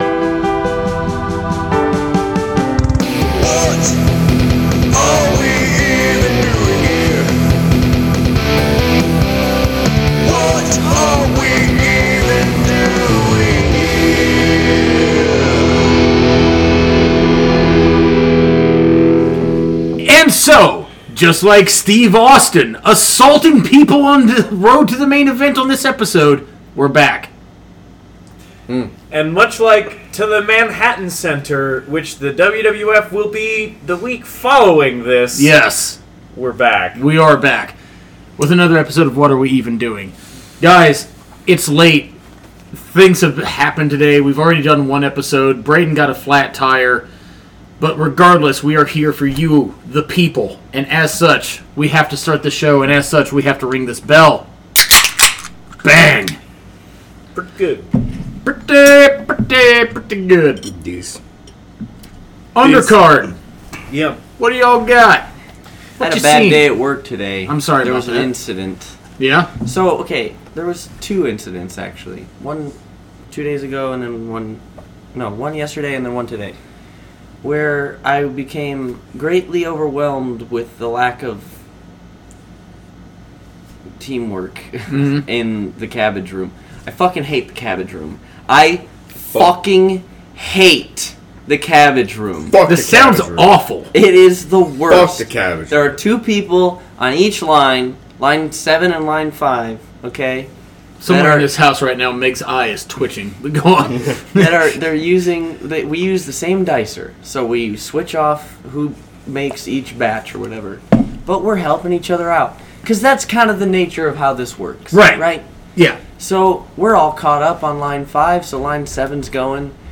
Two Pro Wrestlers.